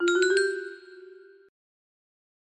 Track 1 � music box melody